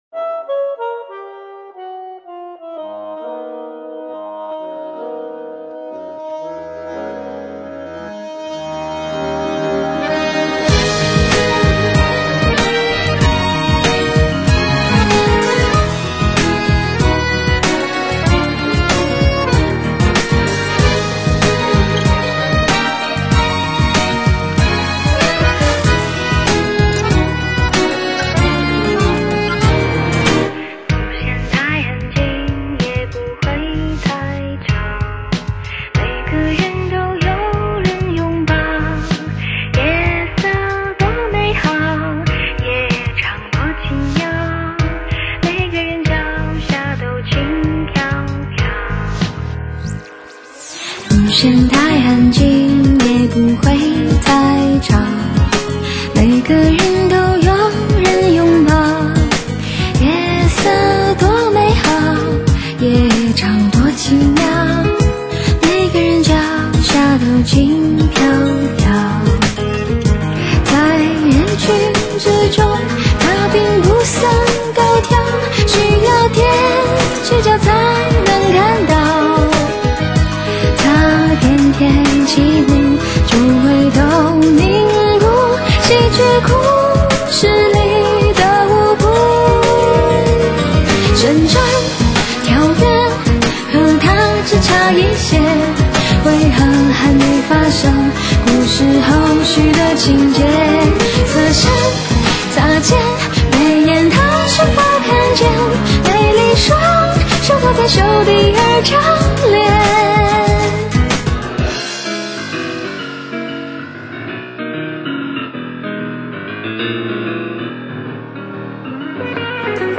华丽复古和时尚电音的完美典范